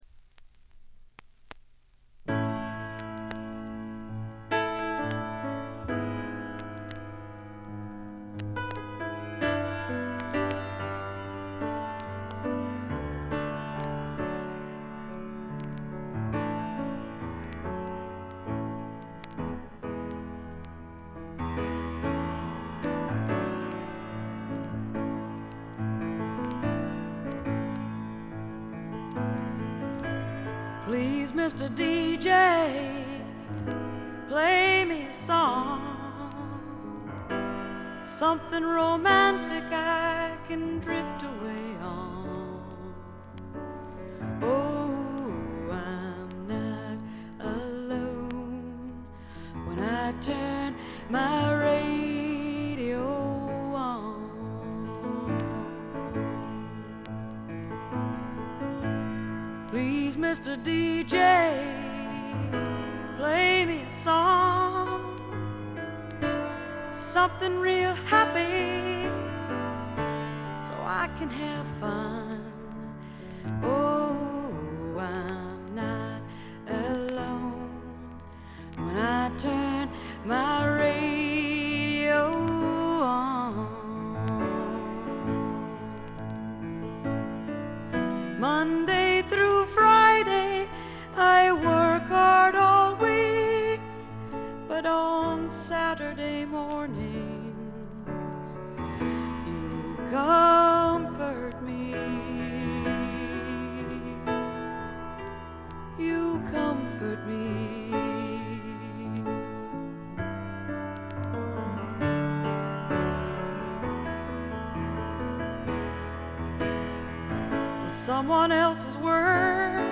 録音はサンフランシスコとナッシュビル。
本作では自作の曲をフォーキーに時に軽いスワンプテイストも加えながら豊かな世界をつむぎ出しています。
試聴曲は現品からの取り込み音源です。
Keyboards, Vocals